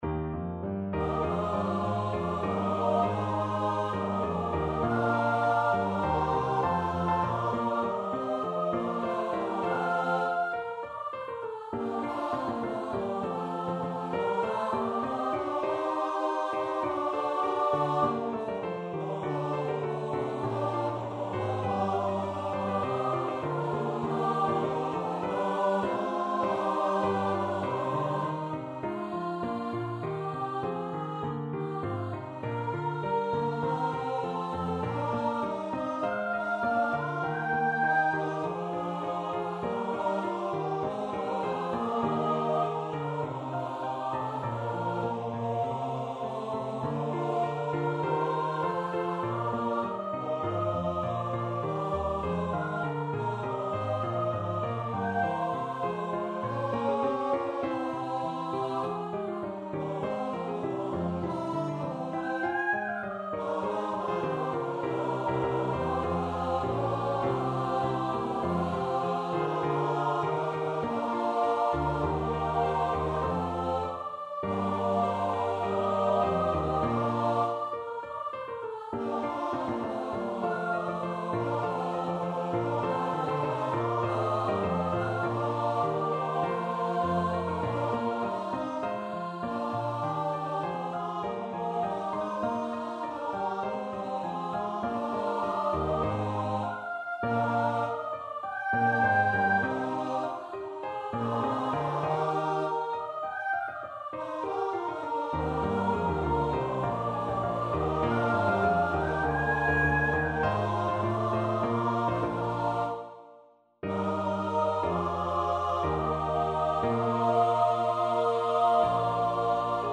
Choir  (View more Intermediate Choir Music)
Classical (View more Classical Choir Music)